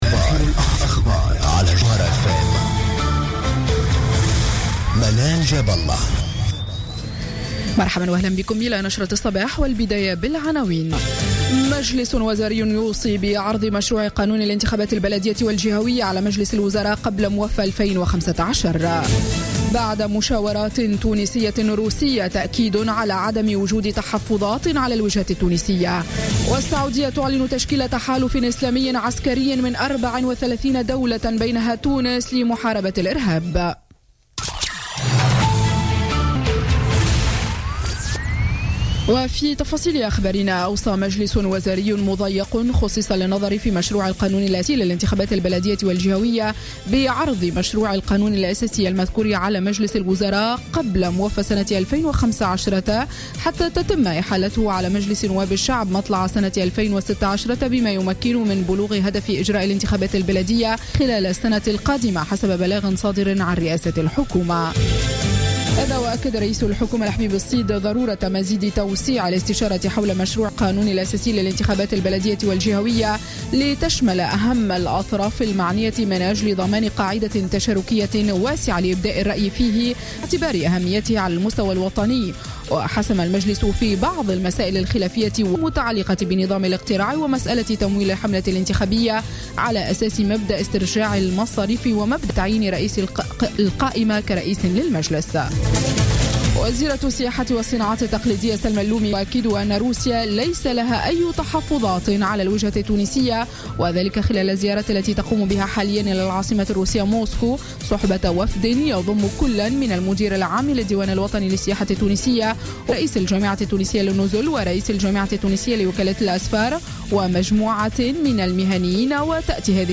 نشرة أخبار السابعة صباحا ليوم الثلاثاء 15 ديسمبر 2015